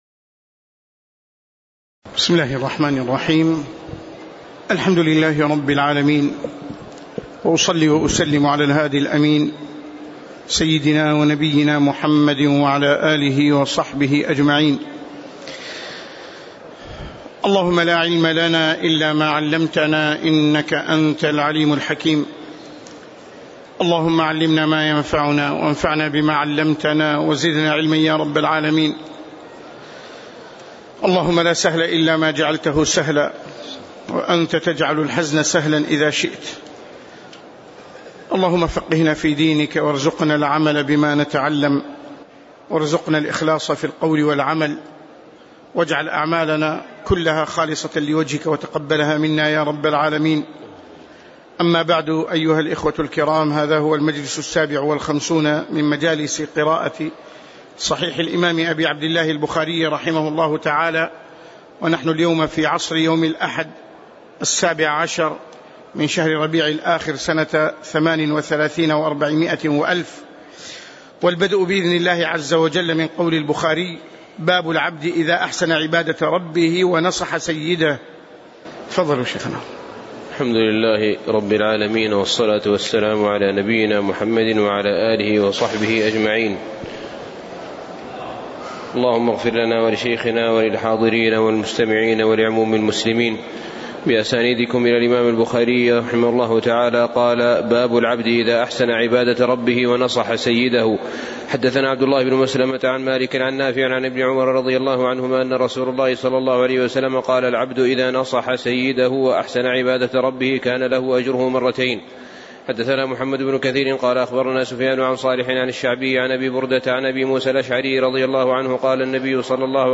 تاريخ النشر ١٧ ربيع الثاني ١٤٣٨ هـ المكان: المسجد النبوي الشيخ